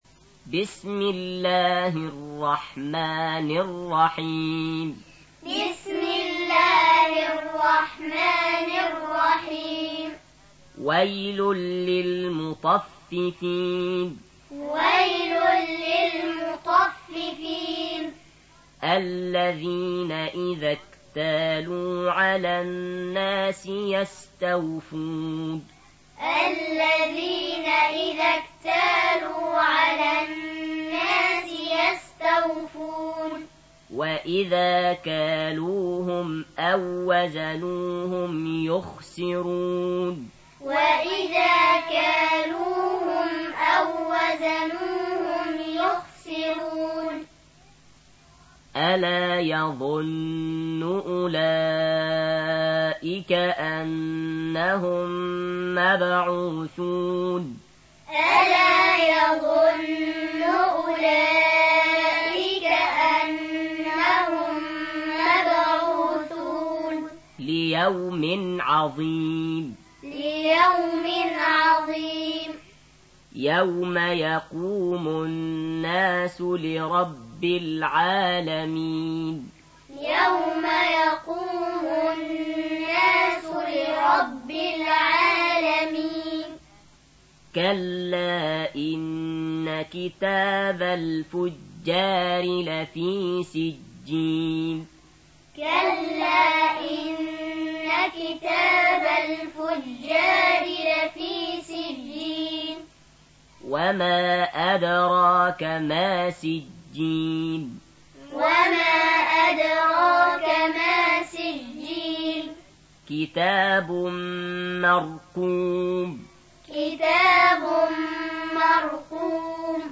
83. Surah Al-Mutaffif�n سورة المطفّفين Audio Quran Taaleem Tutorial Recitation Teaching Qur'an One to One